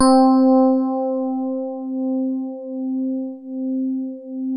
FINE HARD C3.wav